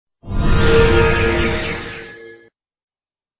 levelUp.D4iRR8o1.wav